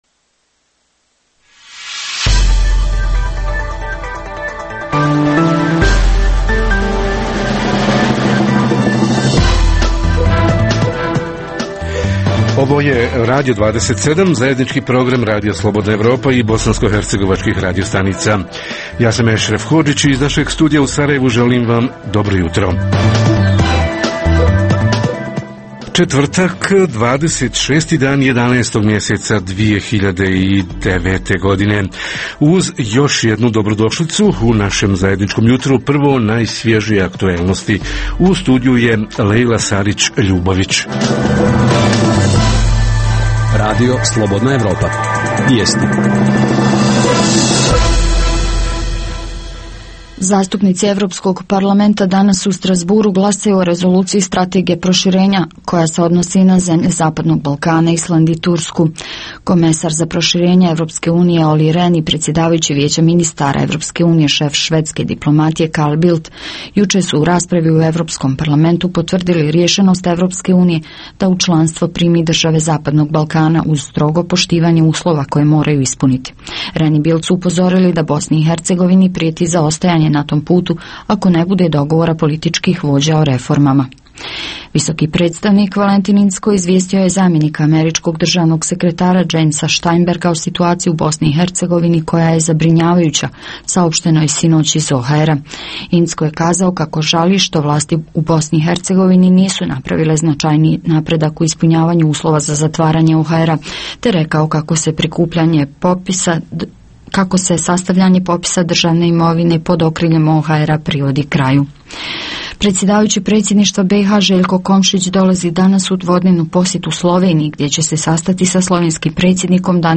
Povratak raseljenih – šta koči “implementaciju”, provedbu projekata i realizaciju sredstava? Reporteri iz cijele BiH javljaju o najaktuelnijim događajima u njihovim sredinama.
Redovni sadržaji jutarnjeg programa za BiH su i vijesti i muzika.